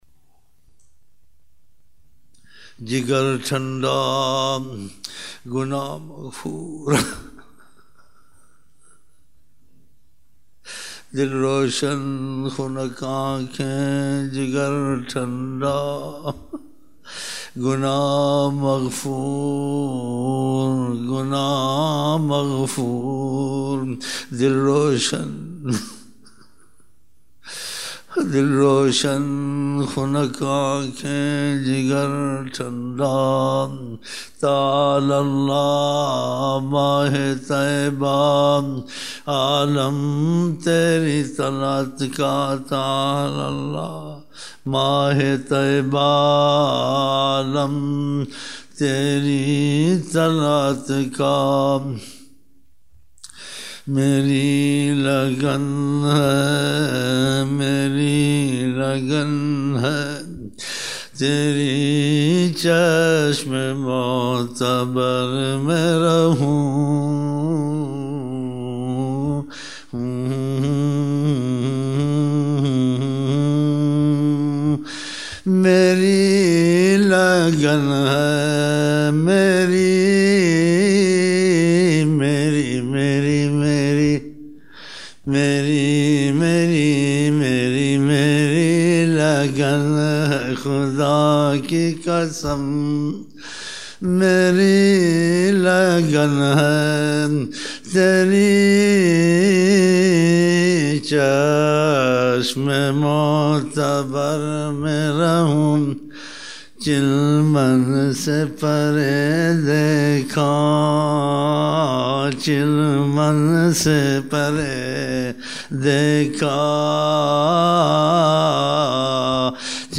16 November 1999 - Fajar mehfil (8 Shaban 1420)